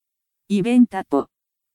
Eventapoは「イベンタポ」と発音します。
発音を聞く(KanaVoiceによる音声)
eventapo-pronunciation.mp3